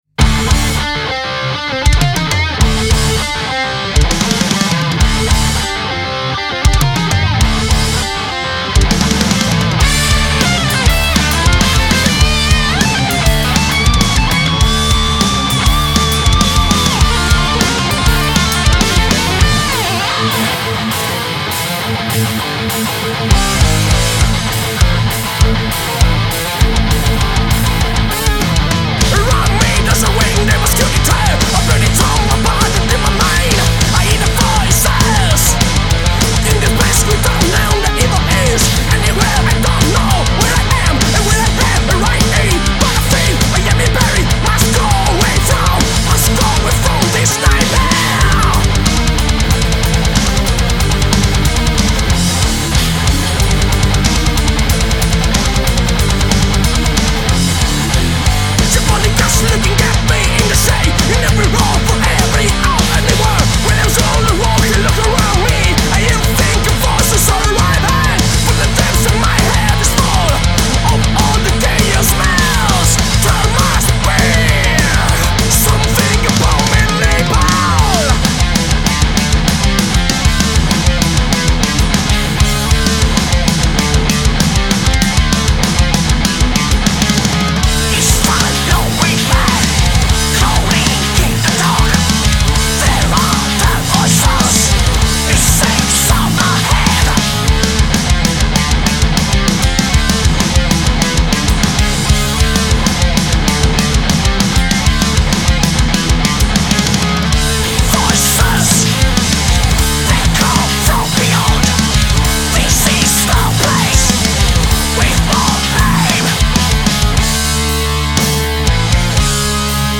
GenereRock / Metal